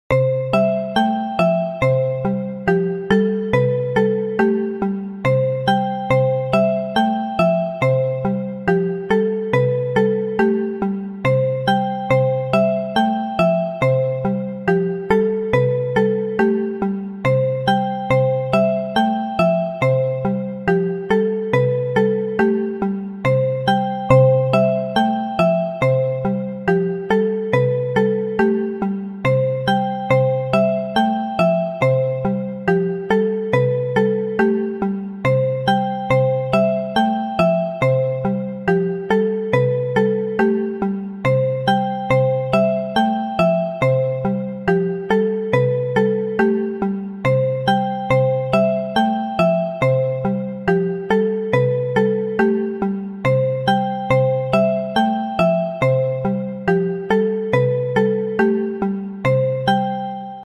ポップなパズルゲームとかにあいそうなイメージです。ループ対応。
BPM140